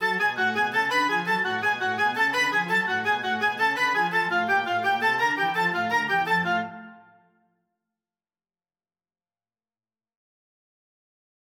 베토벤 교향곡 8번 피날레 386-394마디
교향곡은 매우 긴 으뜸조 화음으로 끝난다.